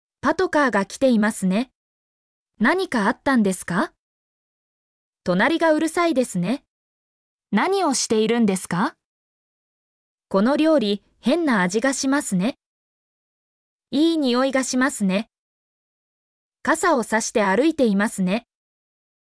mp3-output-ttsfreedotcom-6_xuTWlTp7.mp3